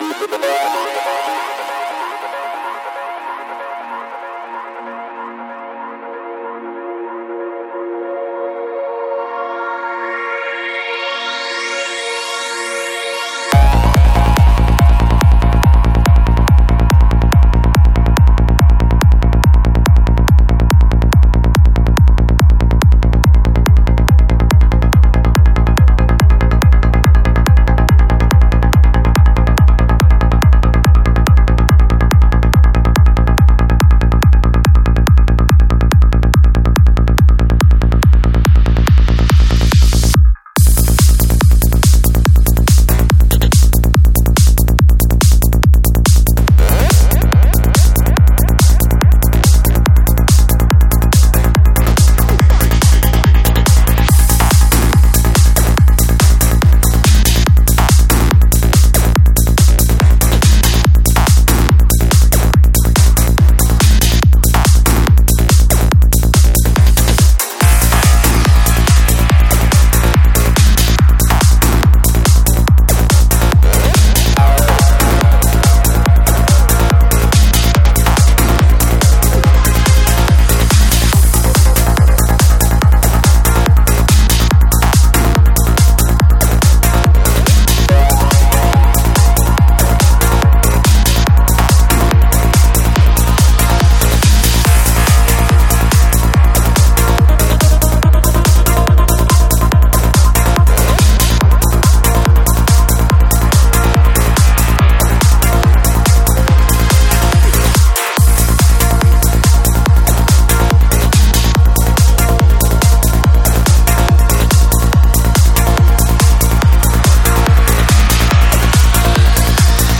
Жанр: Psy-Trance